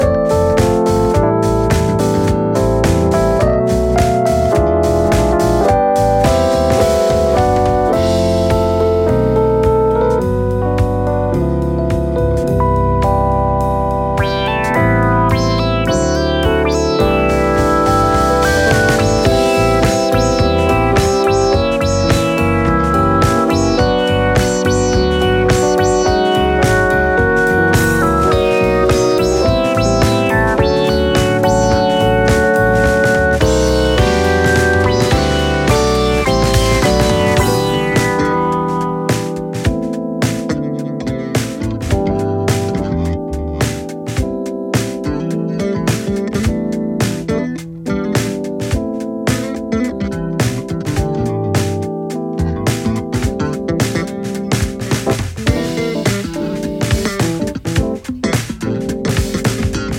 ジャンル(スタイル) JAZZ / POP JAZZ